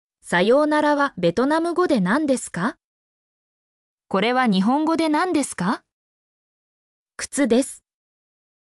mp3-output-ttsfreedotcom-64_nXxghj7n.mp3